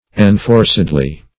-- En*for"ced*ly , adv.